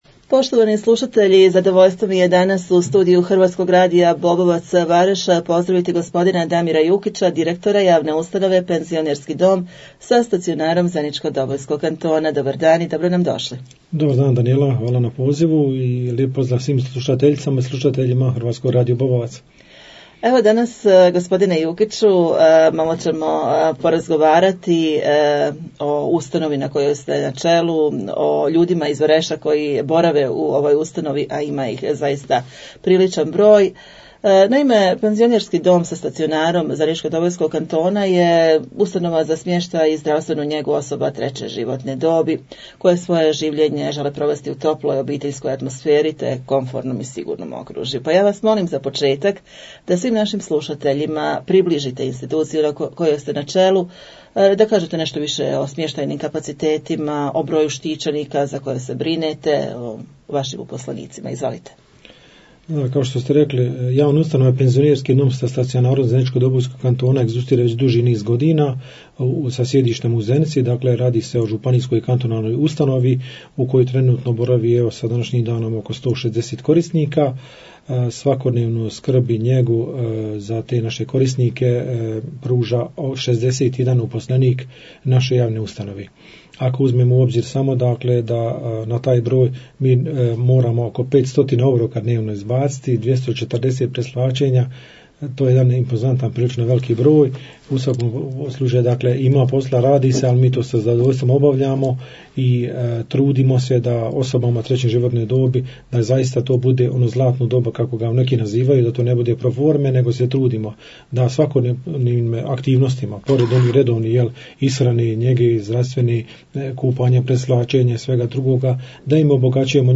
U studiju našeg radija u povodu Dana umirovljenika Federacije BiH